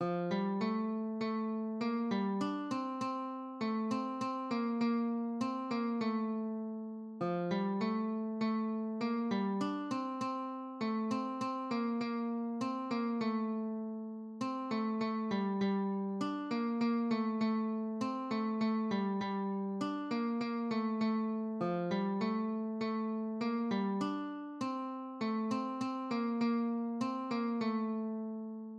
"Weißt du, wie viel Sternlein stehen" (German for "Do you know how many stars there are?") is a German lullaby and popular evening song.
MIDI-based rendition
Church organ EG 511